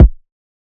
BWB VAULT KICK (Clean).wav